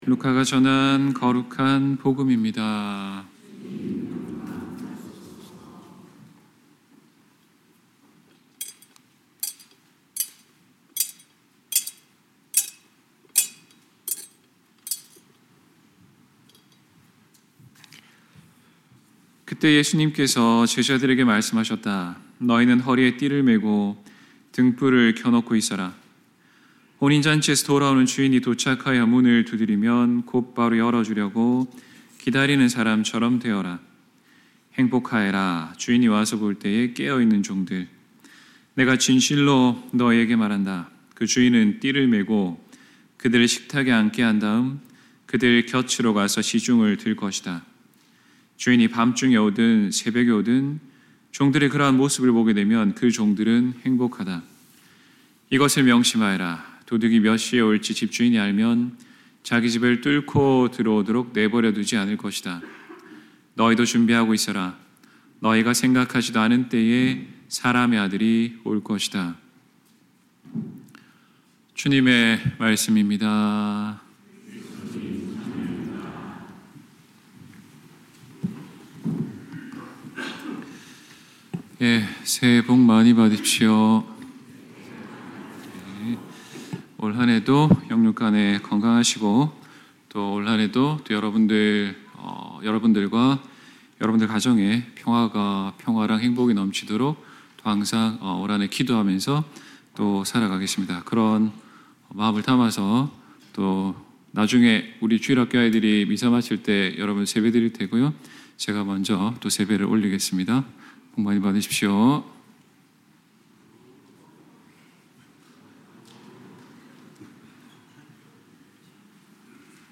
2025년 1월 26일 연중 제3주 신부님 강론